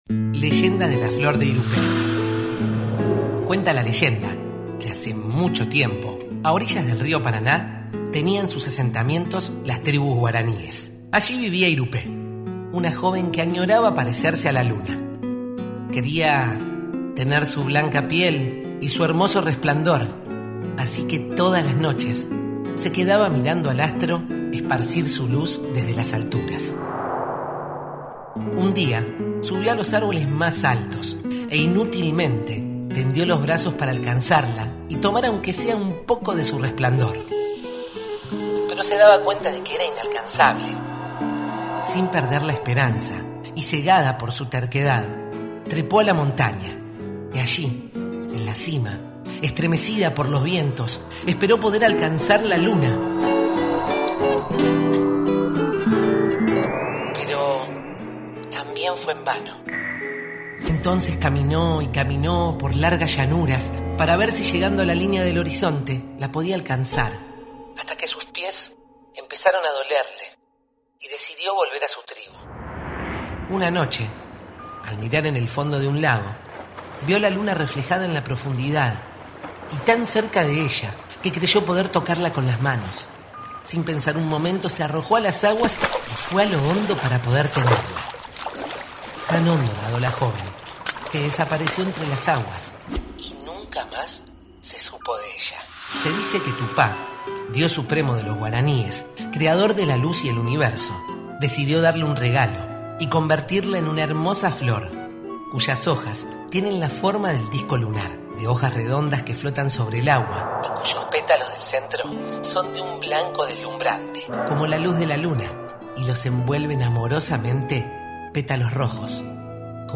Cuentos contados: Leyenda de la flor de Irupé